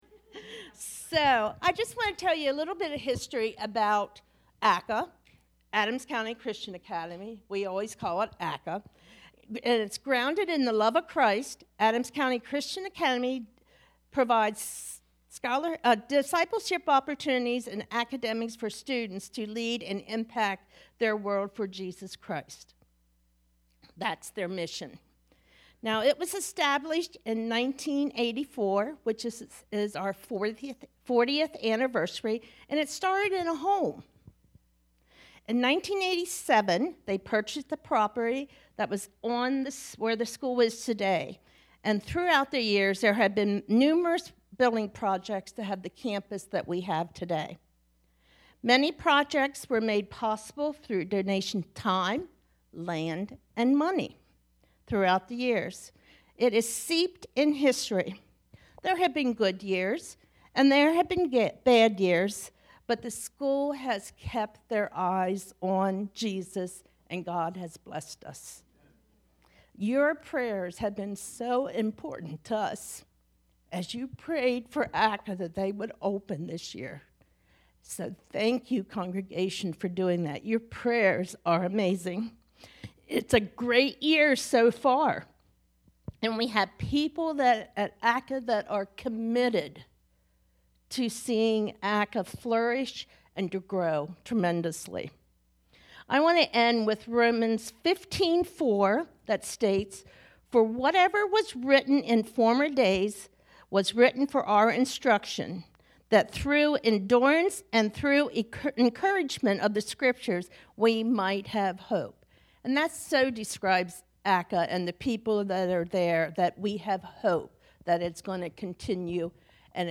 Idaville Church » Sermons